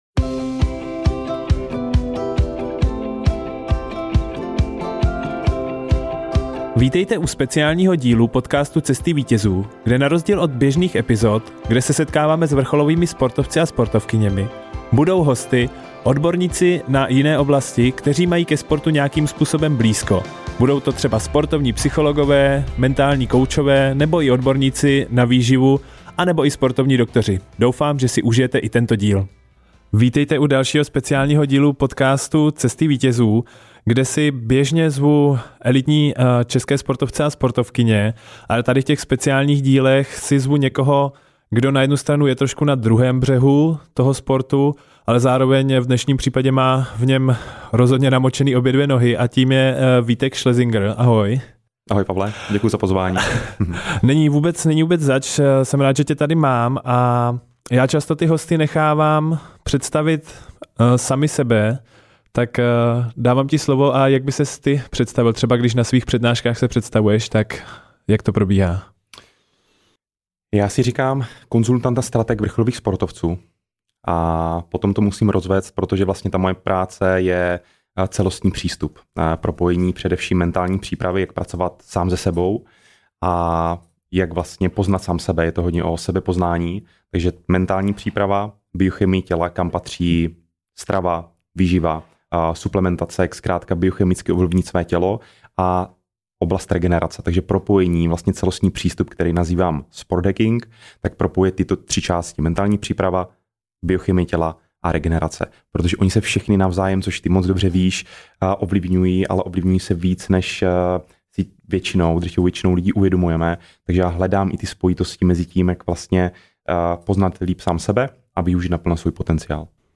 S čím vším vám může pomoct dýchání, proč je důležité naučit se i prohrávat a že není cíl jako cíl. Užijte si rozhovor!